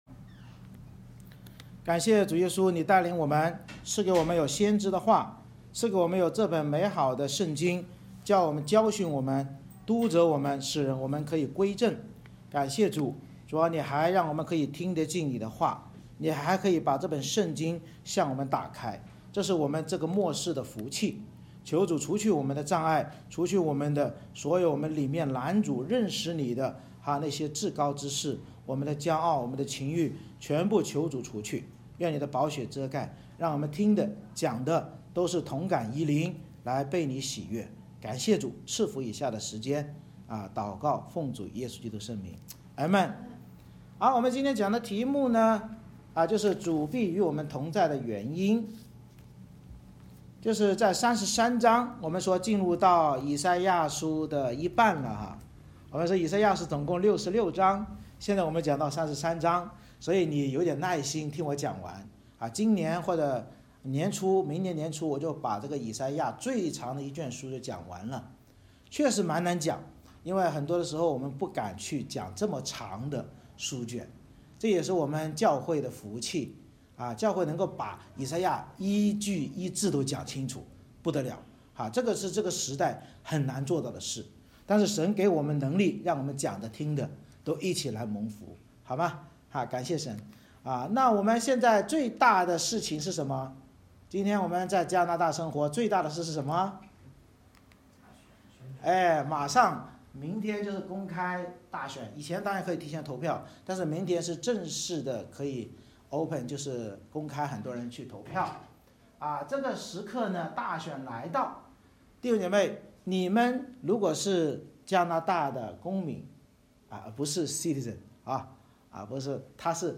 《以赛亚书》讲道系列 Passage: 以赛亚书Isaiah 33:1-24 Service Type: 主日崇拜 神藉着先知预言诡诈之人的灾祸，呼吁我们要敬畏、悔改自省地祷告，这样弥赛亚就必与我们同在，因祂是以公义审判治理并拯救我们的王。